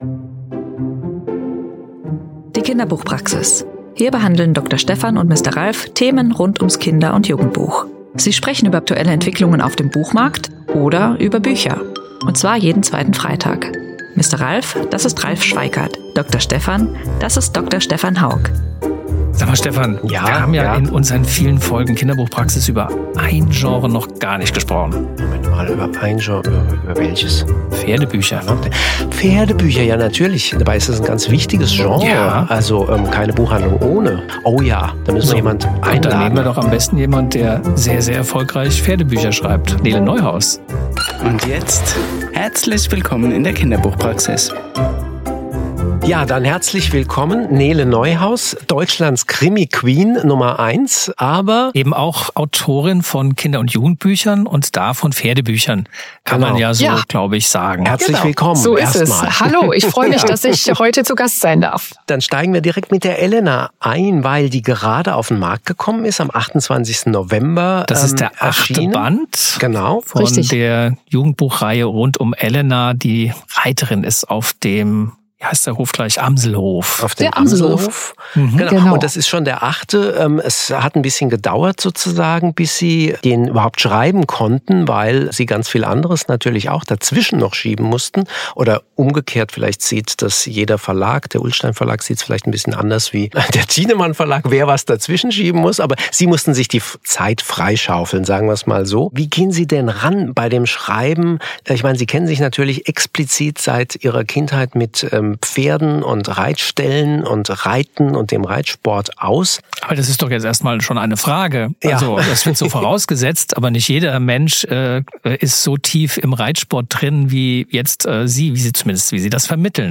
Mitten auf der Buchmesse in Bologna